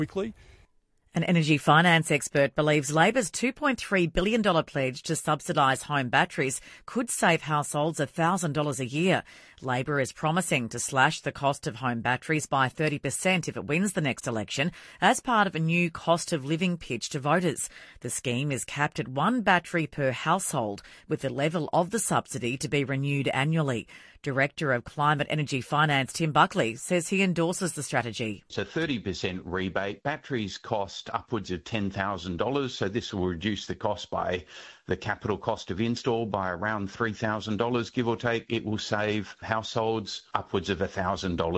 INTERVIEW | ABC NewsRadio on battery policy